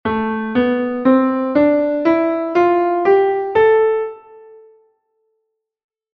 Escalas menores
Natural: é a escala coas alteracións propias da armadura.
Escala La m sen alteracións